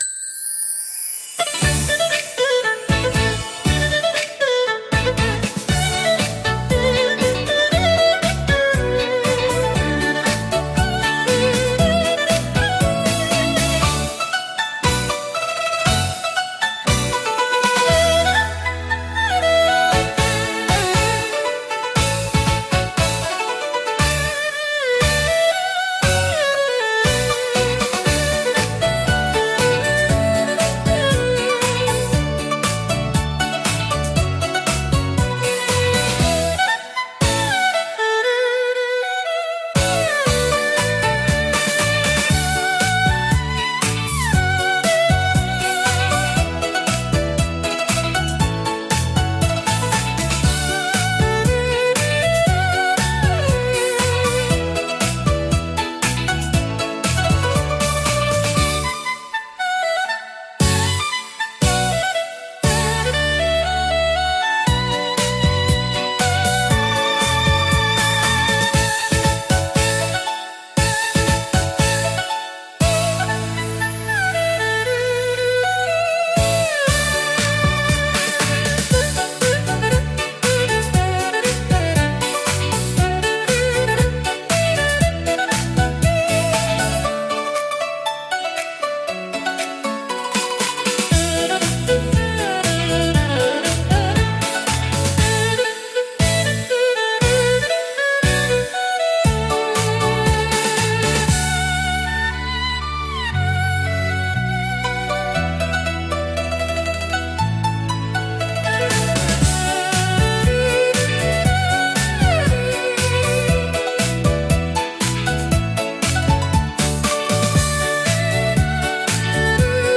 聴く人の心に深い感動と癒しを届ける優雅で情緒豊かなジャンルです。